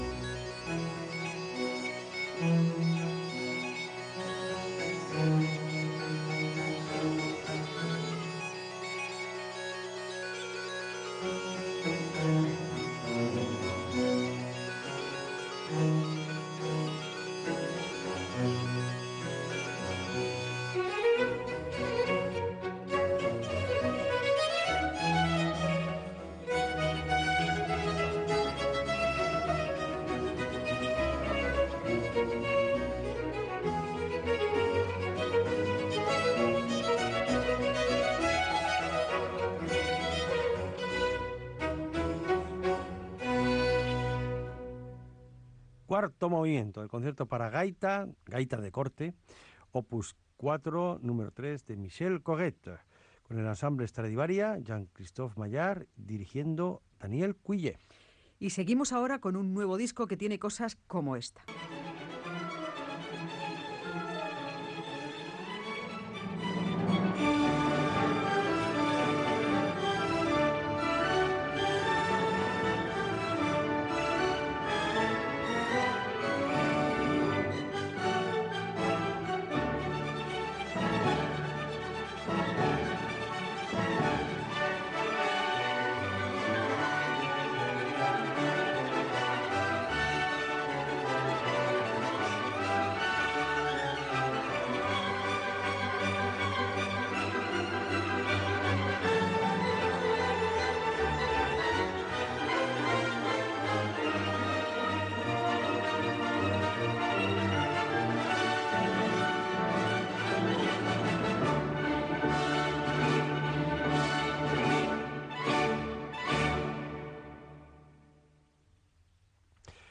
Tema musical, comentari del tema escoltat, presentació d'un nou disc que s'ha publicat recentment
Musical